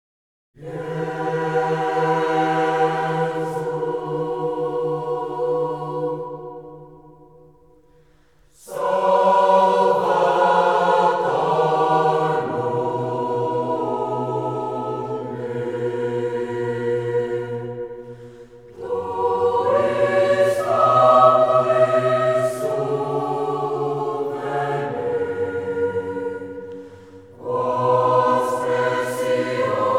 4 Choral Jesu Salvator Mundi Coral S. Domingos